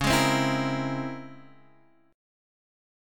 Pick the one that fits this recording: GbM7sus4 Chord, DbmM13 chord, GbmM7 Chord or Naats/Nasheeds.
DbmM13 chord